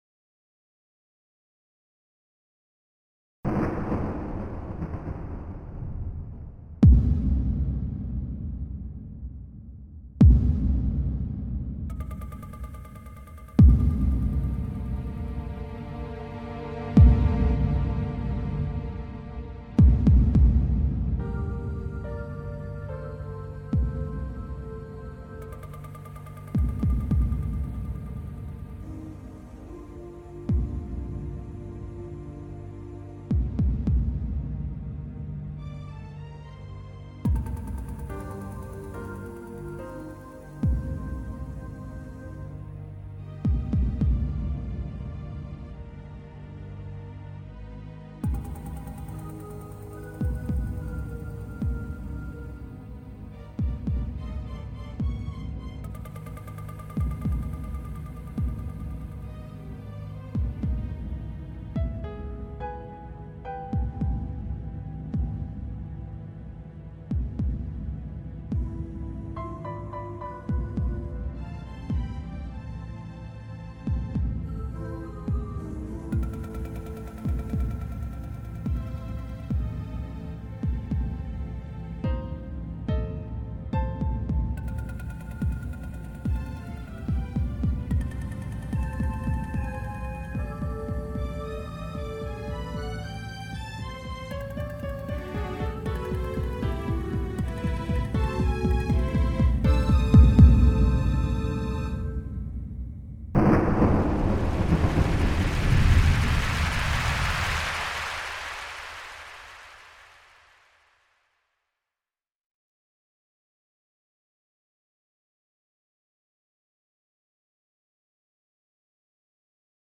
The mood of the music is either action or emotion.
The tempo quickens. You hear muffled echoes of its presence.